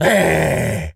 pgs/Assets/Audio/Animal_Impersonations/gorilla_angry_02.wav at master
gorilla_angry_02.wav